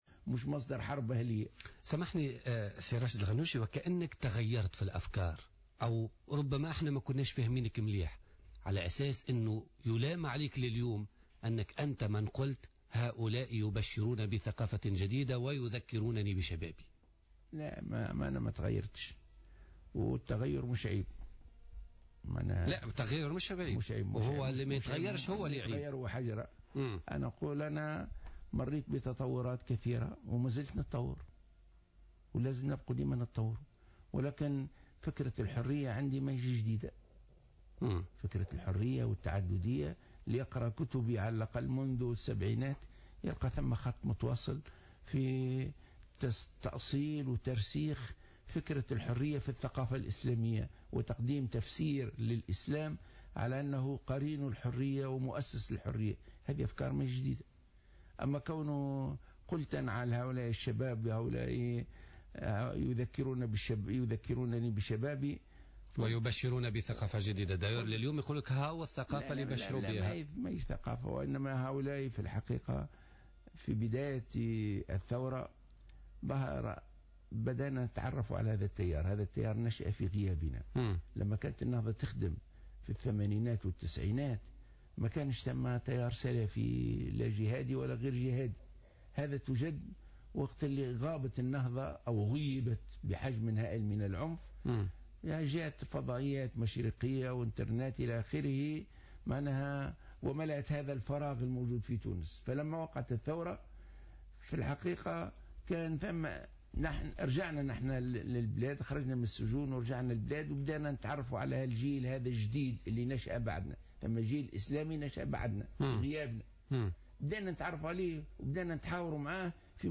أكد رئيس حركة النهضة راشد الغنوشي ضيف بوليتيكا اليوم الخميس 9 جويلية 2015 ان هناك حركات جهادية تحولت في مصر والجزائر الى احزاب سياسية مدنية تعمل في اطار القانون مؤكدا انه حين تحاور مع بعض قيادات انصار الشريعة ابان الثورة كان يعتقد انه يمكن ادماجهم في الحياة السياسة بصفة عادية.